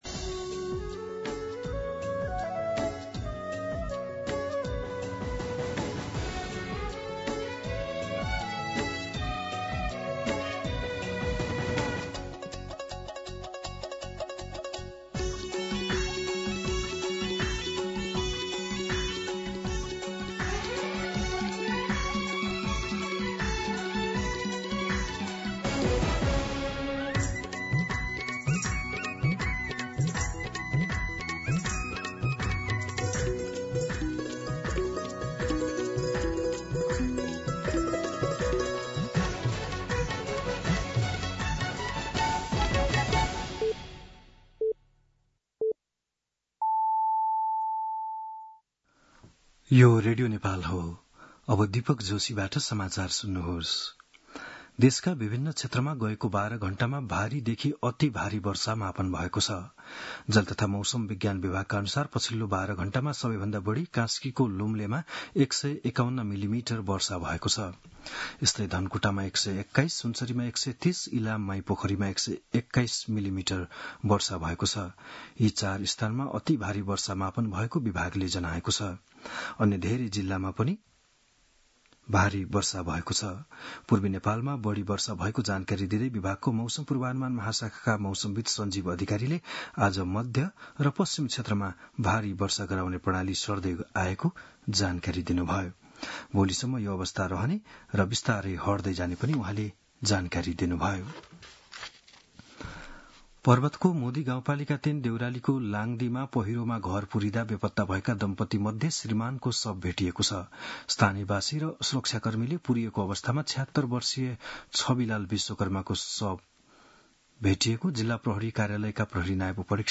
बिहान ११ बजेको नेपाली समाचार : ४ साउन , २०८२
11am-News-04.mp3